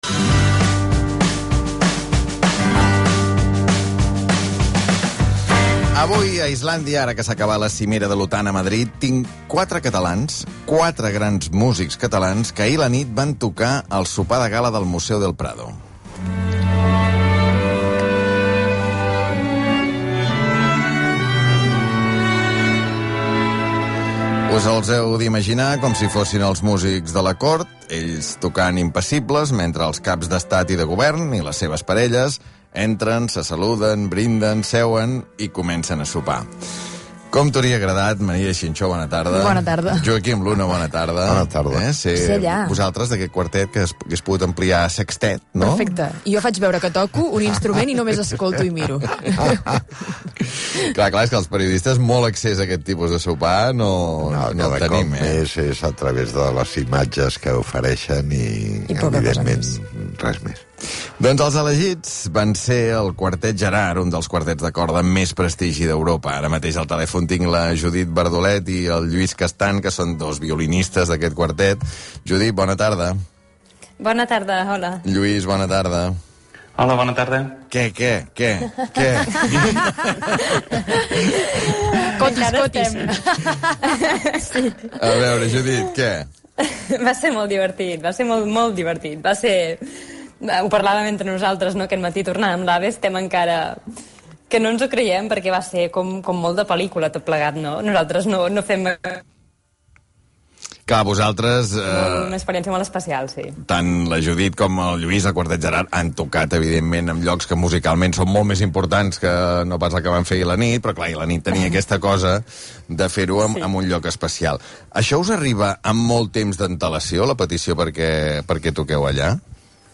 Aquí us deixem l’entrevista per ràdio al programa “Islàndia” de RAC 1 amb l’Albert Om i dos dels integrants del grup de corda